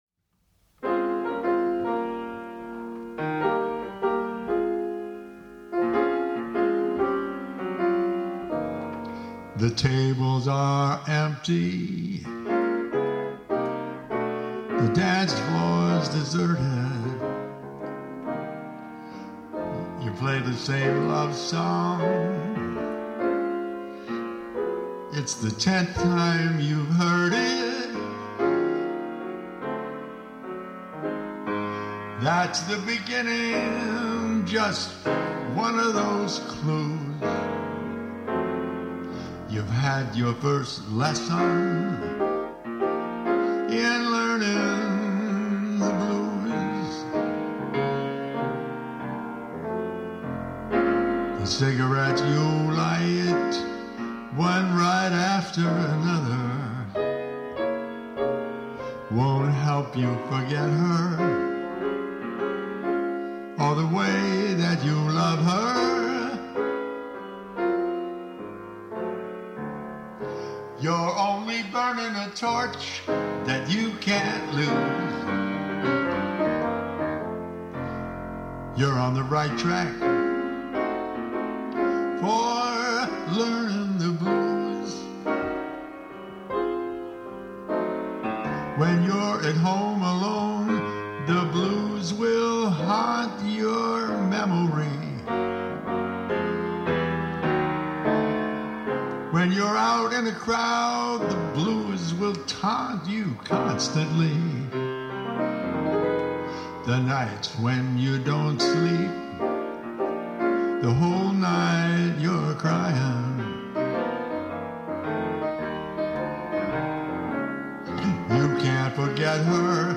Solo Piano & Voice